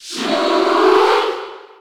Shulk_Cheer_French_NTSC_SSB4.ogg.mp3